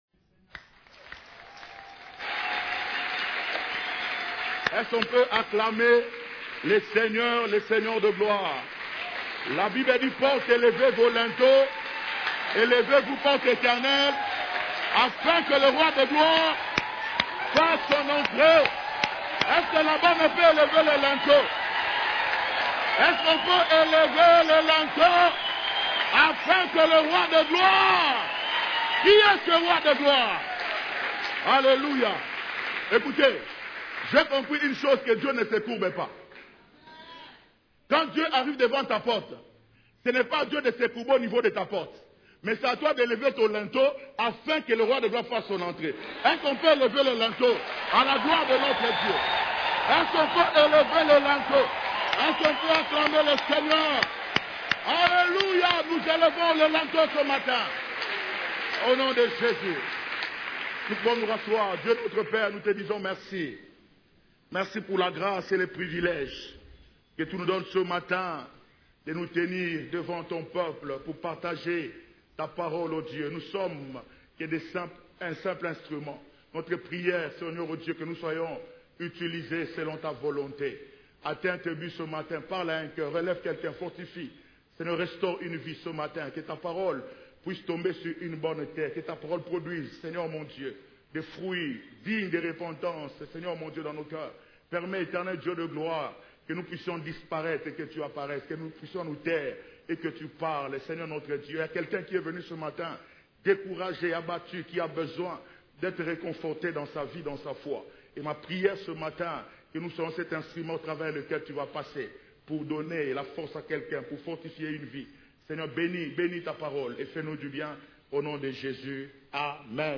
CEF la Borne, Culte du Dimanche, Comment et pourquoi vivre la puissance de Dieu ?